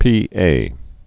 (pēā)